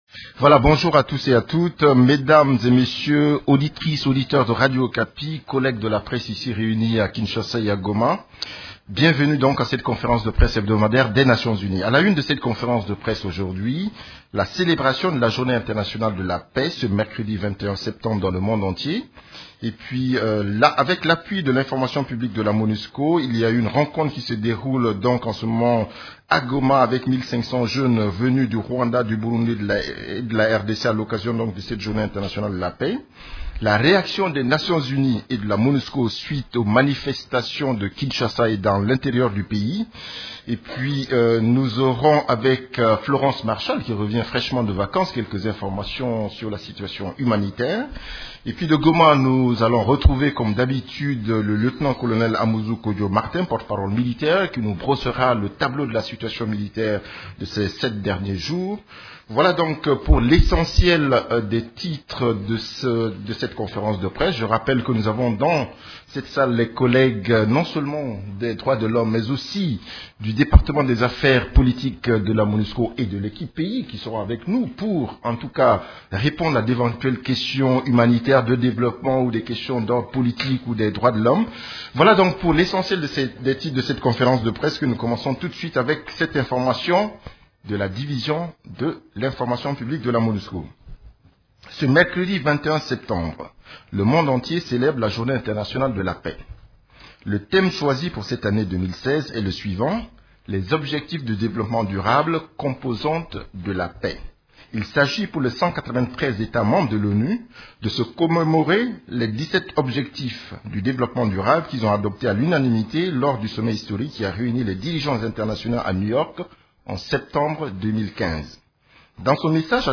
Conférence de presse du 21 septembre 2016
La conférence de presse hebdomadaire des Nations unies du mercredi 21 septembre à Kinshasa a porté sur la situation sur les manifestations de Kinshasa, les activités des composantes de la MONUSCO, des activités de l’Equipe-pays ainsi que de la situation militaire à travers la RDC.
Vous pouvez écouter la première partie de la conférence de presse: